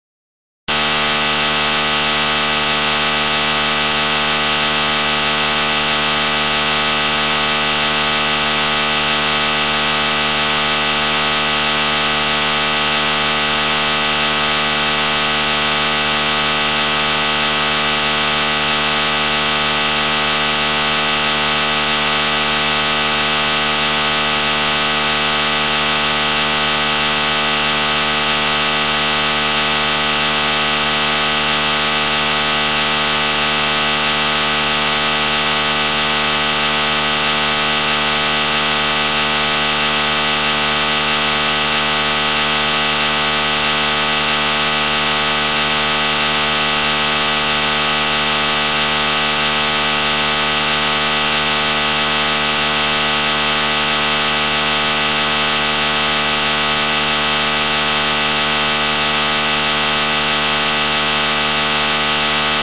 На этой странице собраны звуки, которые могут напугать или отпугнуть собак: ультразвуковые сигналы, резкие шумы, свистки и другие эффекты.
Звук который пугает мелких собак